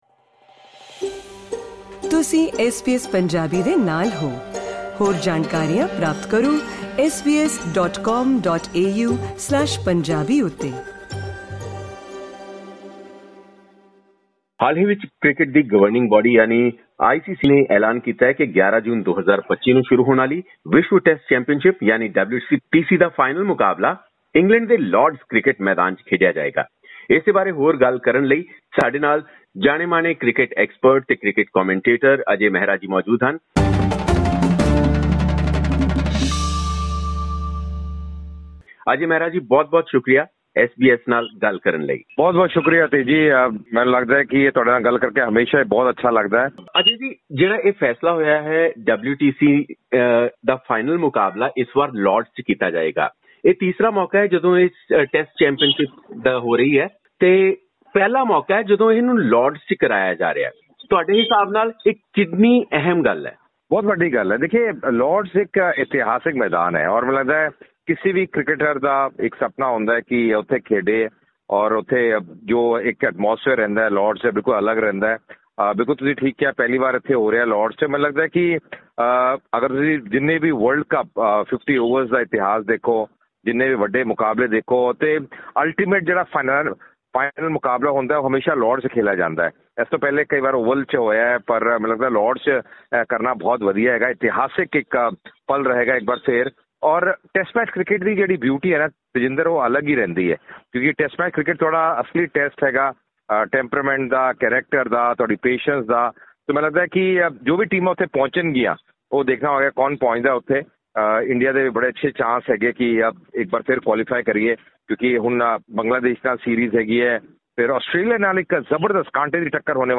ਪੂਰੀ ਗੱਲਬਾਤ ਸੁਨਣ ਲਈ ਆਡੀਓ ਲਿੰਕ ਤੇ ਕਲਿਕ ਕਰੋ।